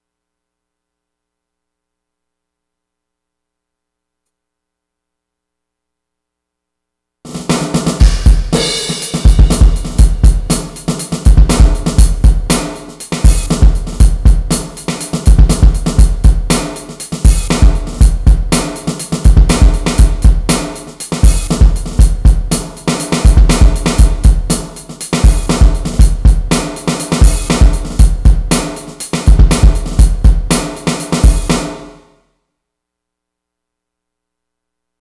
120_beat_heavy_edit.WAV